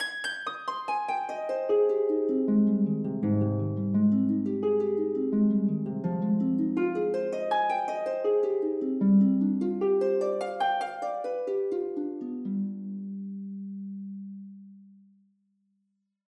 フリーBGM素材- 「殻」を拡大解釈して、城を「殻」にしてるヤドカリの魔女とか居てほしい。
イントロでハープの短いフレーズが別であるので使う時お好みでどうぞ。